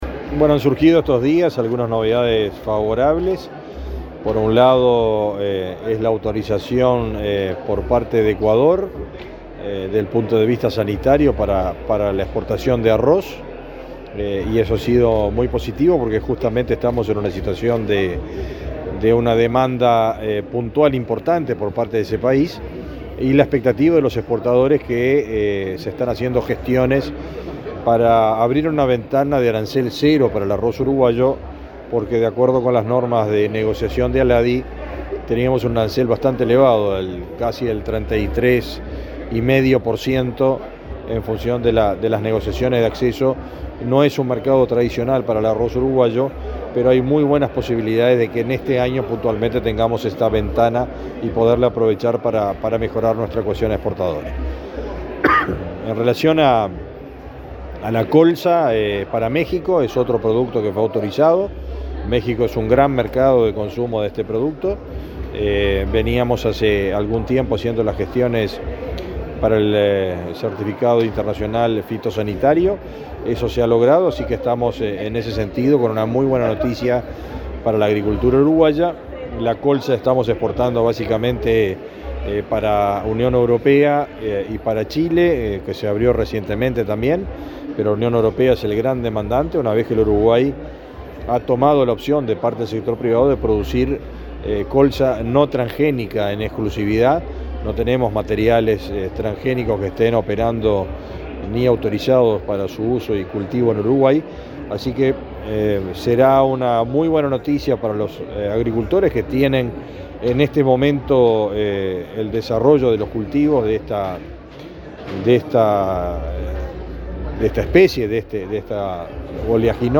Entrevista al ministro de Ganadería, Fernando Mattos